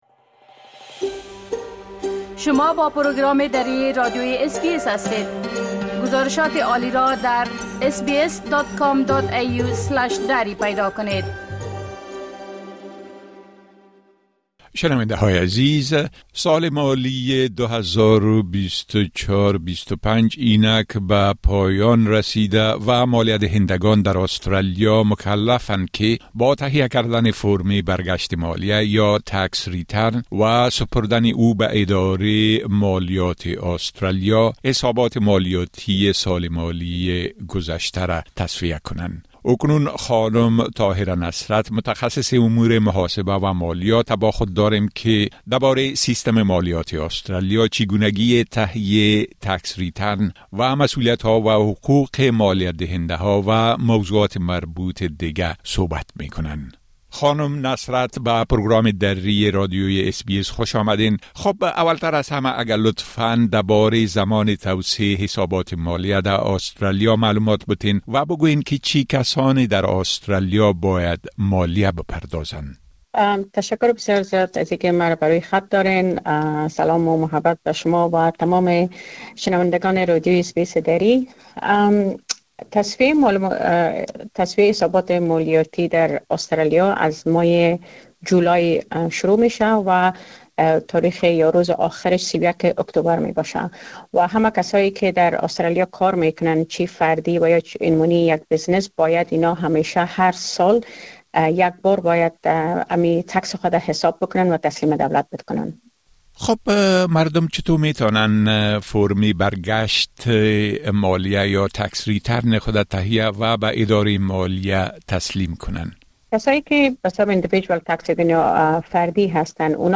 راهنمای برگشت مالیه و حقوق مالیه‌دهندگان در آسترالیا؛ گفتگو با متخصص امور محاسبه و مالیات